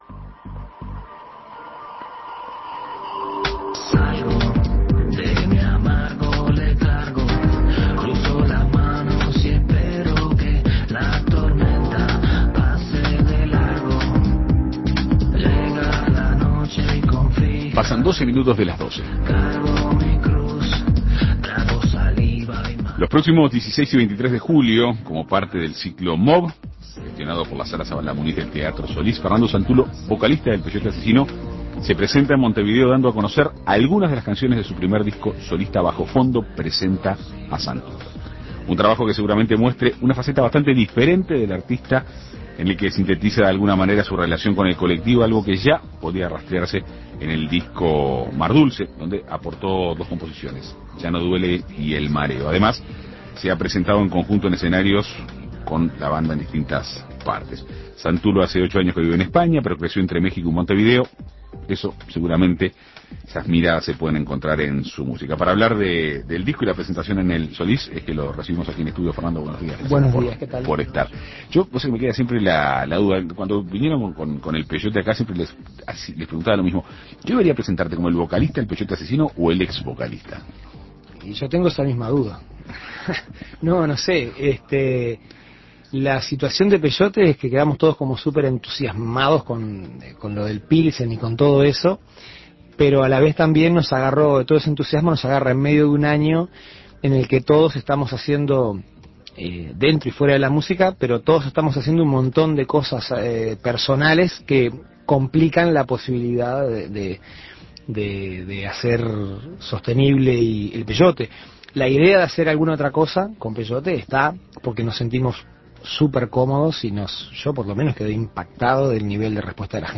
Para conocer detalles de su nueva producción, En Perspectiva Segunda Mañana dialogó con el artista.